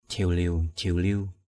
/d͡ʑʱiʊ-liʊ/ (t.) ỉu xìu. tapei racam laman jhiwliw tp] rc’ S{|l{| bánh tráng mềm ỉu xìu.